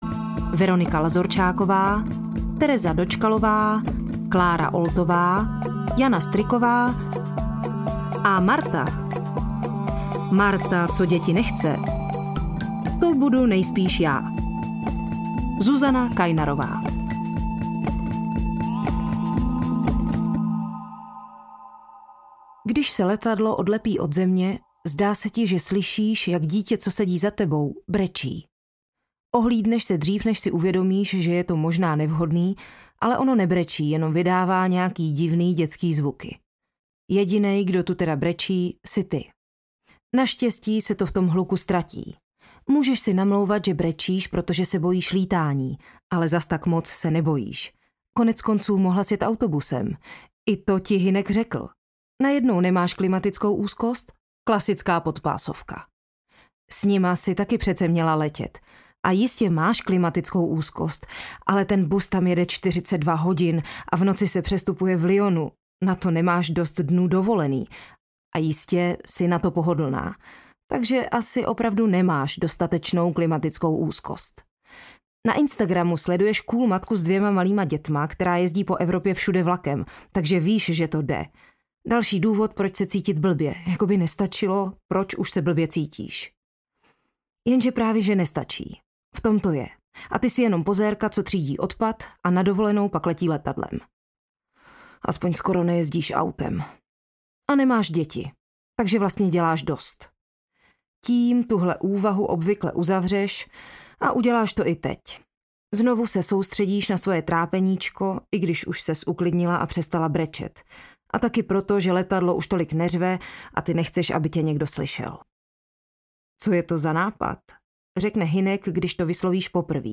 Marta děti nechce audiokniha
Ukázka z knihy